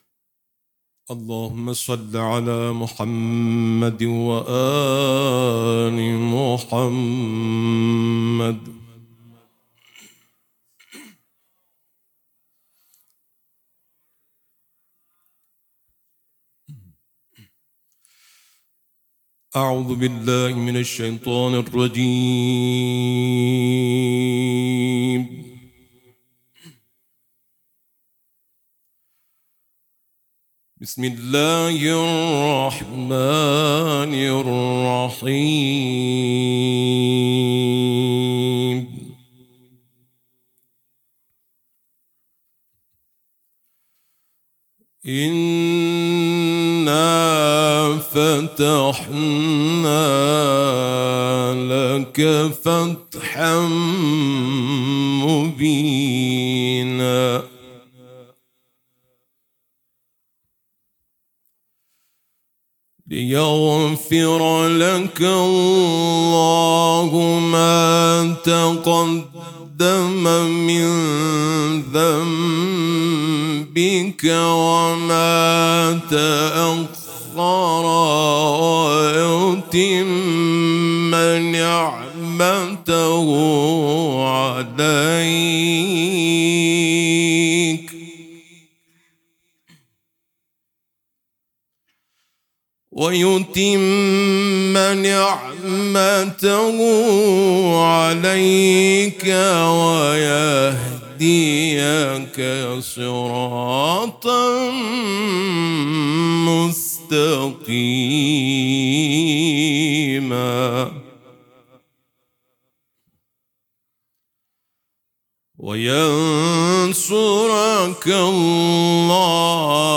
برچسب ها: تلاوت قرآن ، قاری ممتاز قرآن ، صوت تلاوت ، چهارمحال و بختیاری